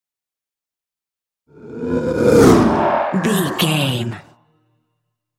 Creature whoosh horror
Sound Effects
Atonal
scary
ominous
eerie
whoosh